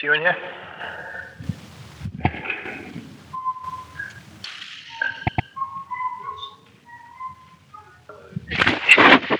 While walking through the lower level, they conduct an EVP session while wandering the area. While doing so, they both hear a sharp and tuneful whistle there with them.
Warner_whistle.wav